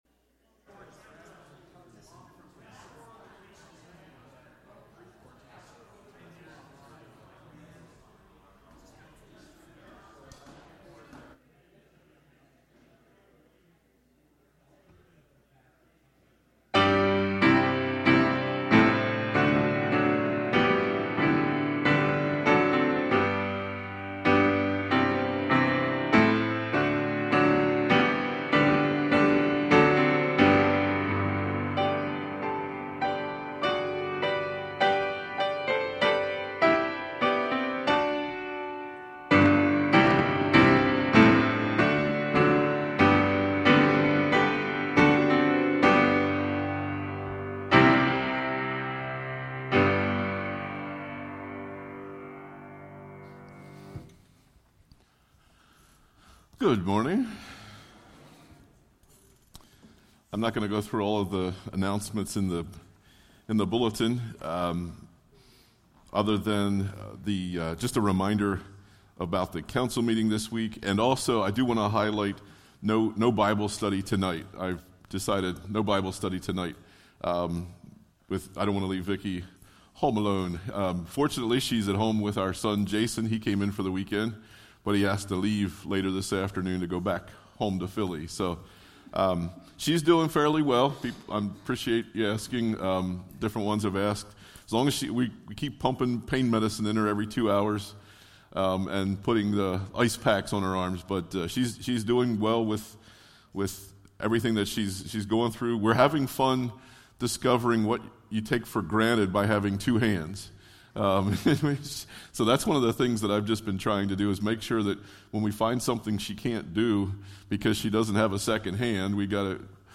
Sermons by Palmyra First EC Church